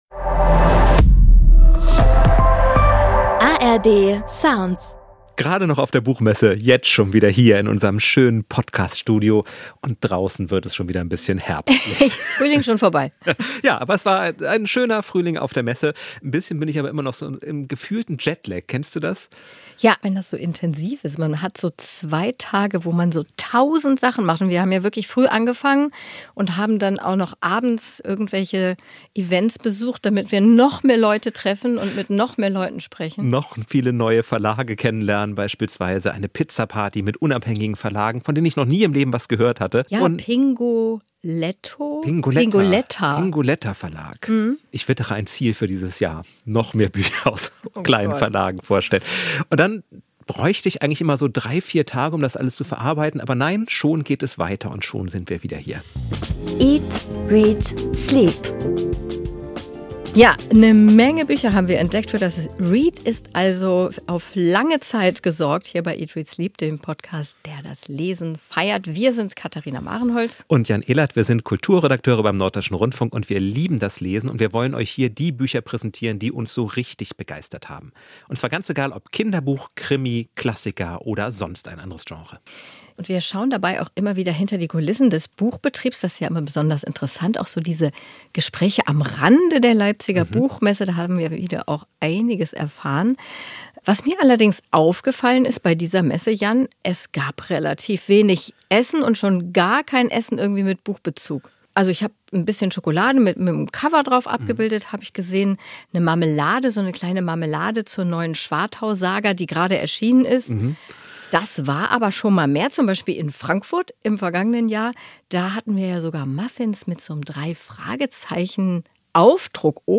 Für Stress sorgt bei einigen Autorinnen und Autoren zunehmend auch der Auftritt auf Social Media. Wie viel Druck geht hier von Verlagen aus und wie gehen erfolgreiche Schreibende damit um? Zu diesen Fragen hat sich eat.READ.sleep. auf der Leipziger Buchmesse umgehört.